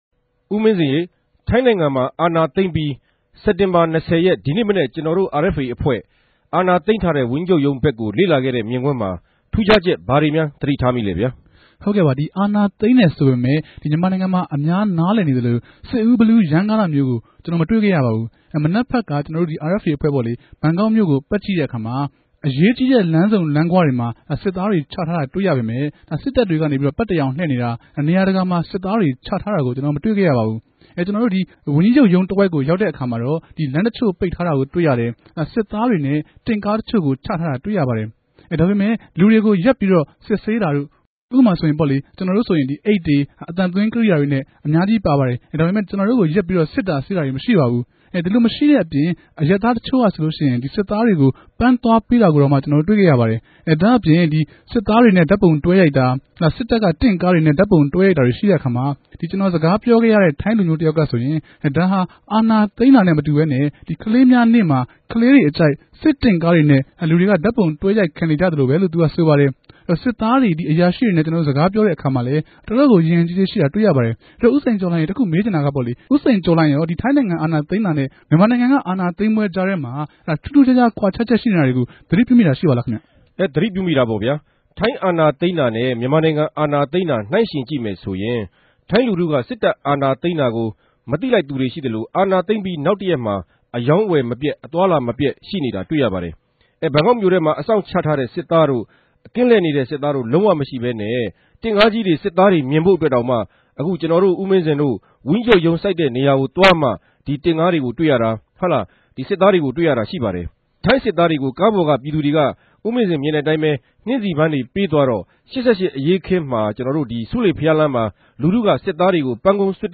ထိုင်းိံိုင်ငံ အာဏာသိမ်းမအြပေၞ ဆြေးေိံြးသုံးသပ်ခဵက်